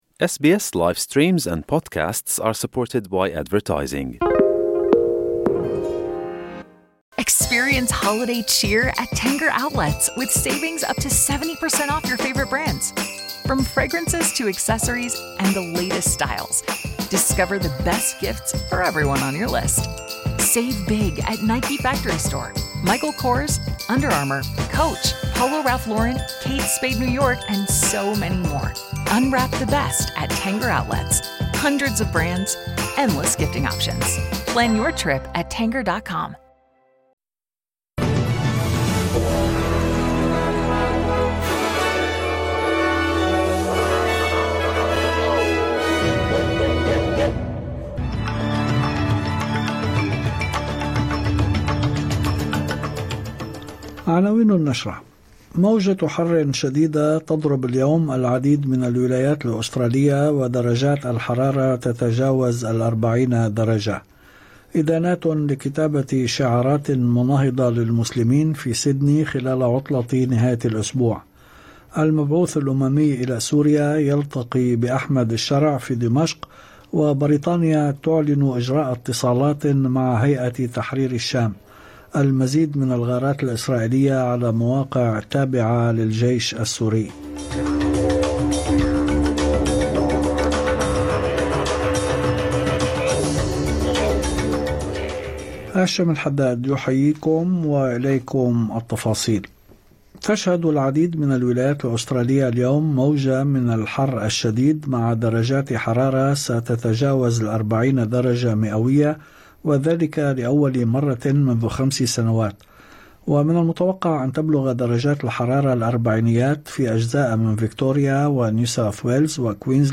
نشرة أخبار المساء 16/12/2024